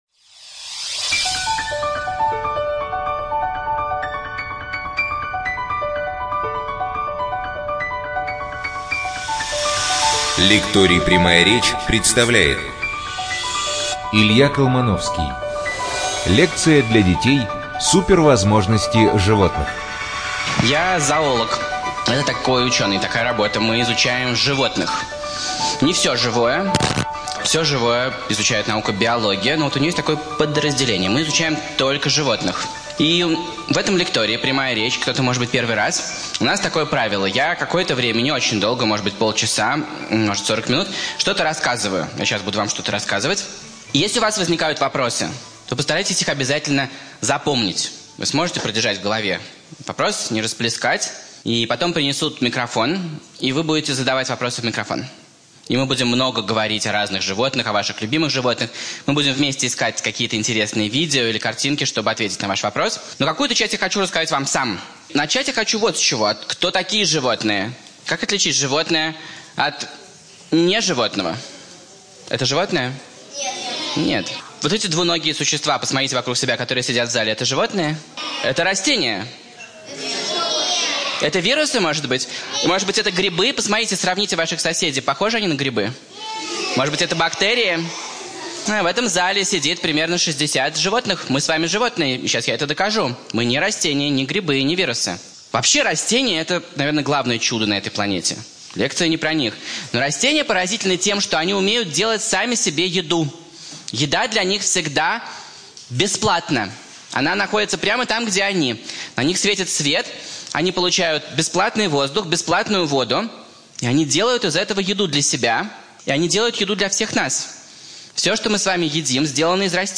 НазваниеСупервозможности животных. Лекция
ЧитаетАвтор